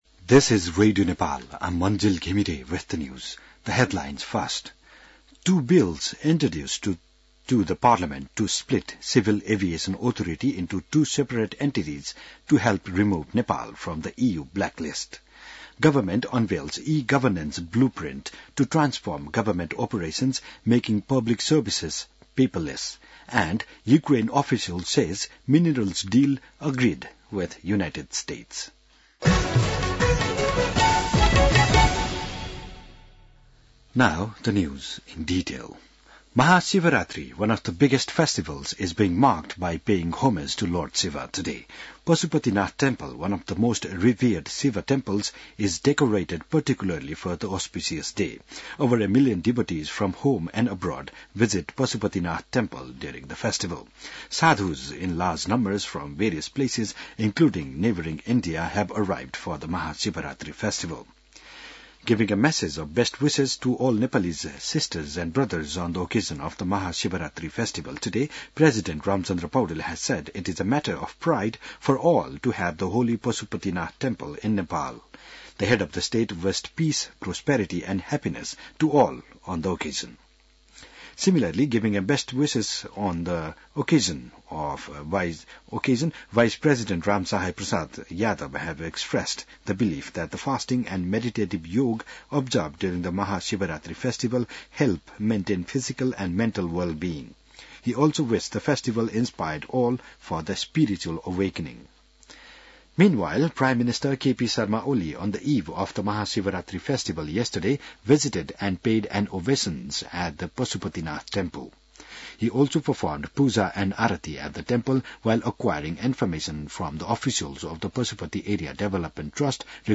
बिहान ८ बजेको अङ्ग्रेजी समाचार : १५ फागुन , २०८१